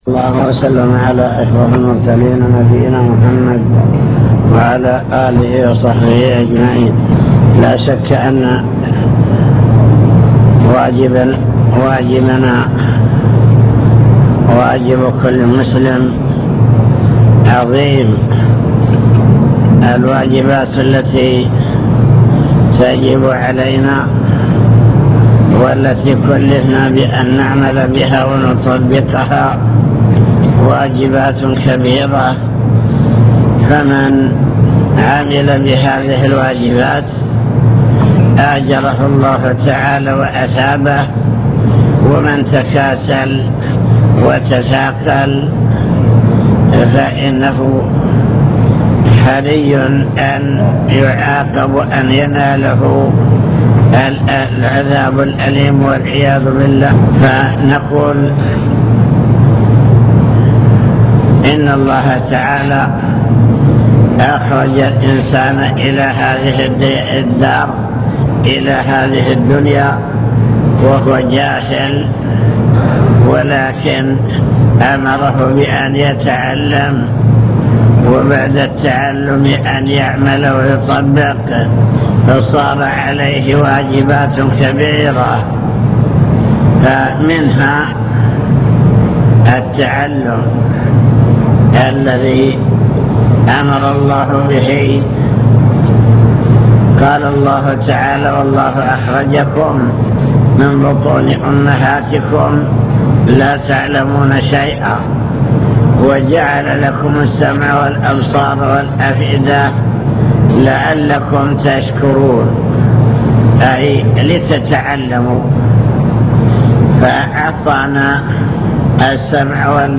المكتبة الصوتية  تسجيلات - محاضرات ودروس  محاضرة في السعيرة